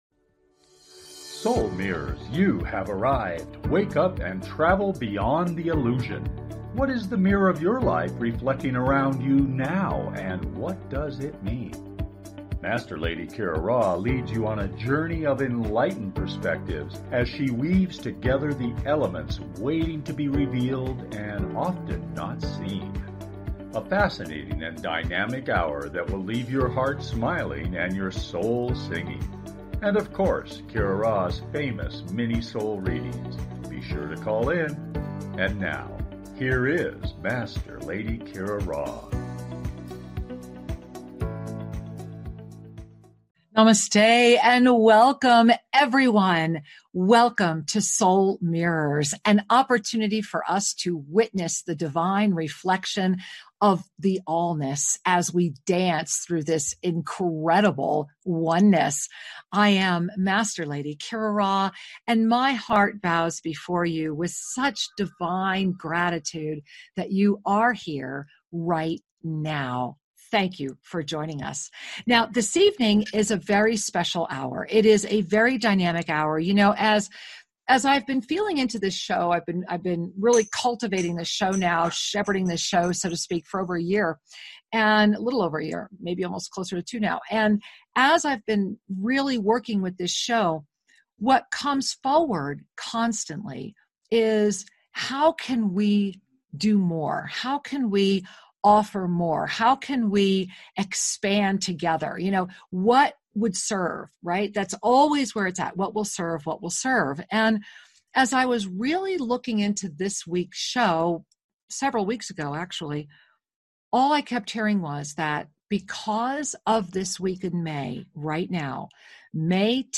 Talk Show
Lively, entertaining, and refreshingly authentic, the hour goes quickly!